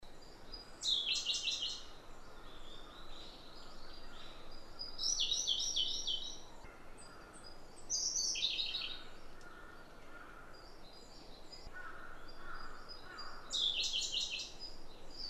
534koruri_saezuri.mp3